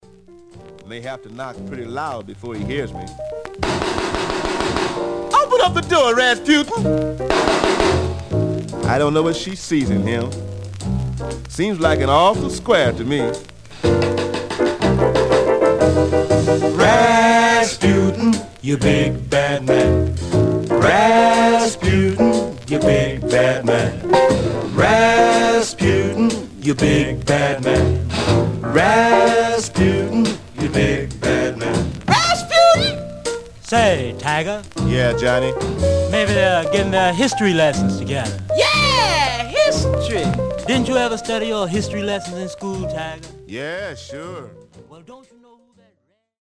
Surface noise/wear
Mono
R&B Instrumental